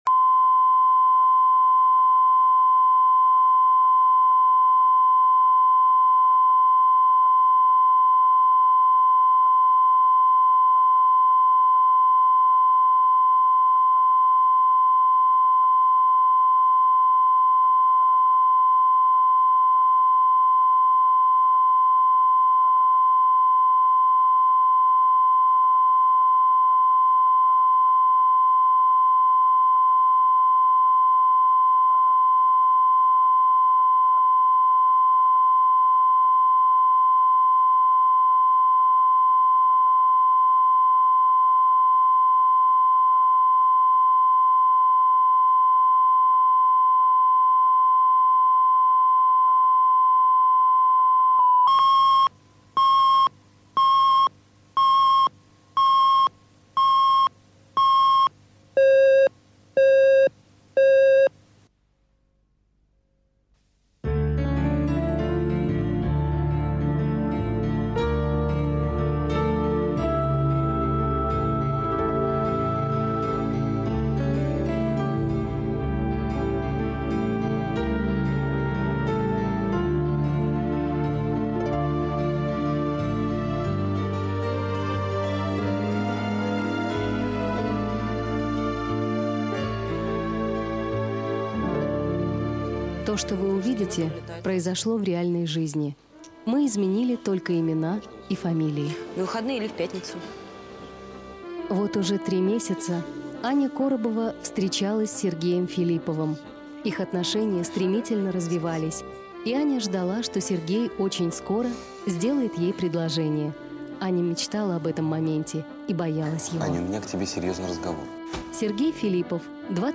Аудиокнига Все еще будет | Библиотека аудиокниг